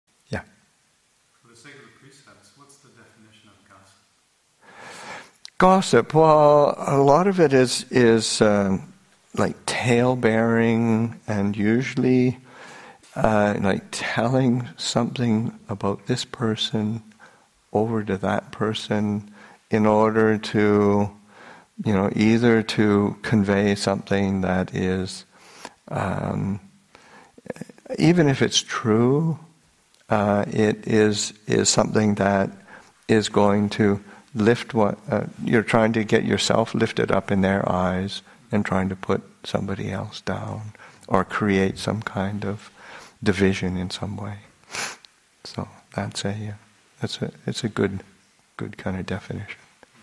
Teen Weekend 2017 – Sep. 2, 2017